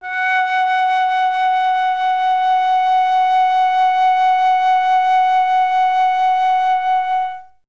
51c-flt07-F#4.wav